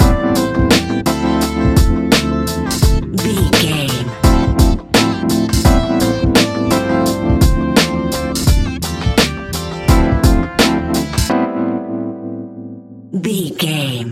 Ionian/Major
A♭
chilled
laid back
Lounge
sparse
chilled electronica
ambient
atmospheric